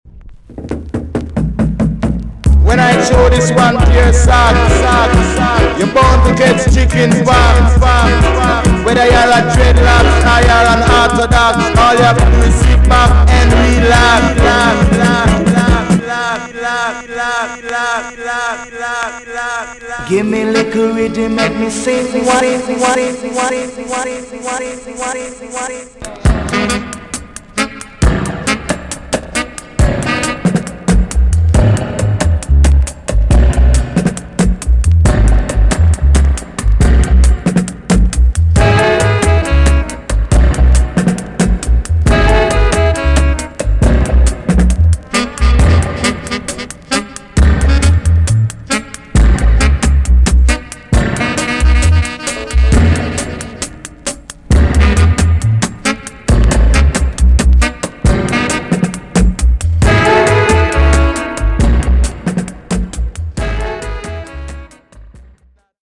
TOP >REGGAE & ROOTS
B.SIDE Version
EX 音はキレイです。